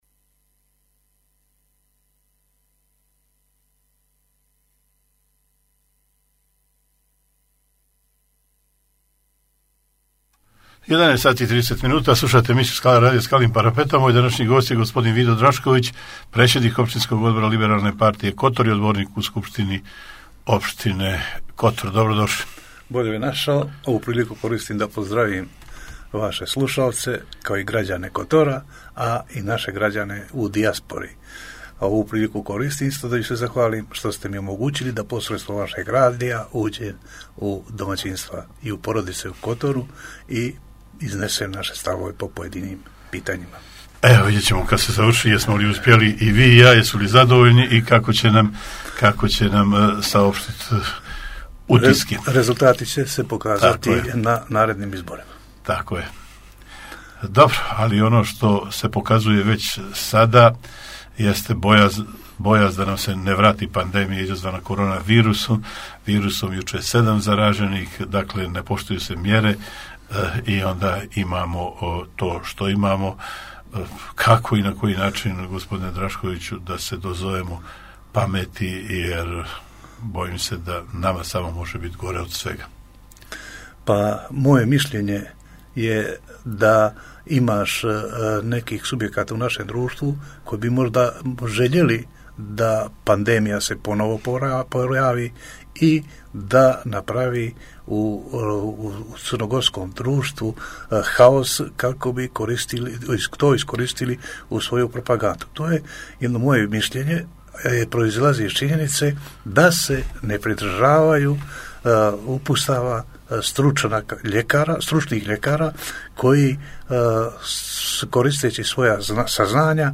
Gost emisije Vido Drašković, predsjednik OO LP Kotor i odbornik u SO Kotor